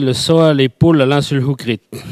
Langue Maraîchin
Locution